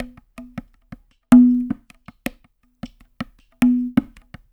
EPH DHOLE.wav